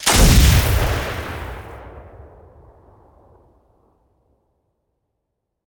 sniper2.ogg